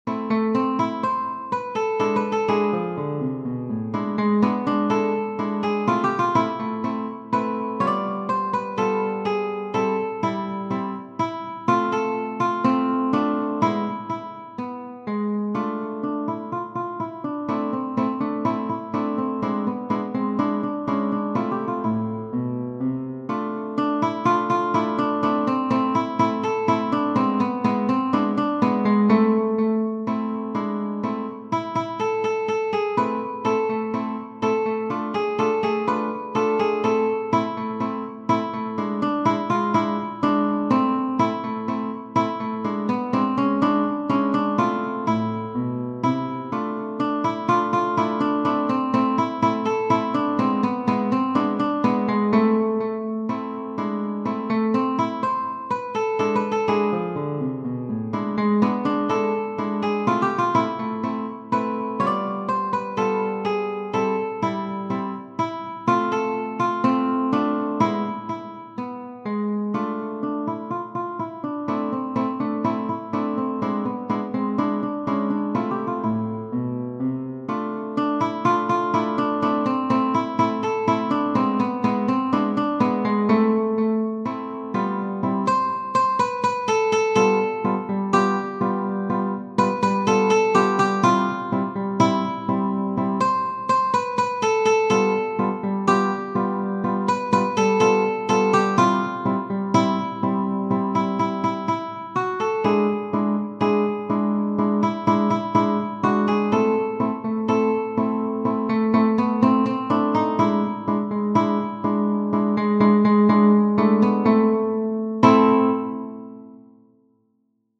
Genere: Ballabili